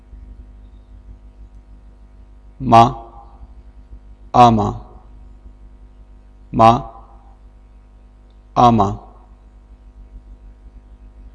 Consonnes - Sujet #4